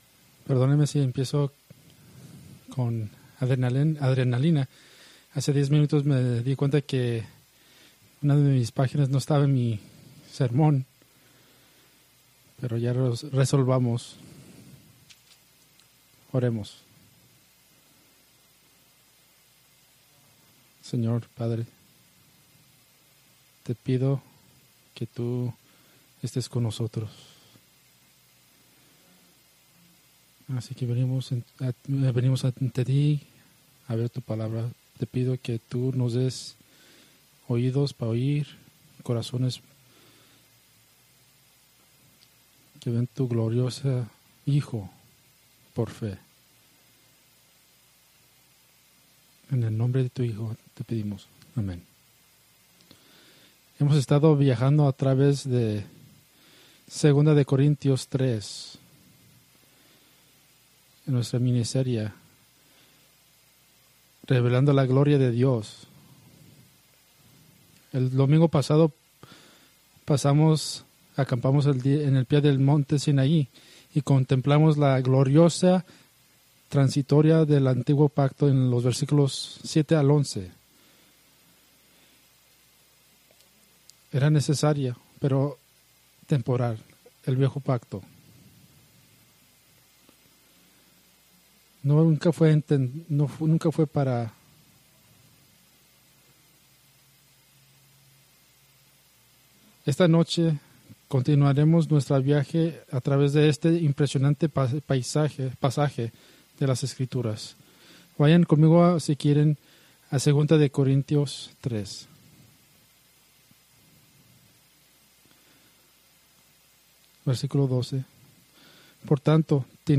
Preached May 25, 2025 from 2 Corintios 3:12-18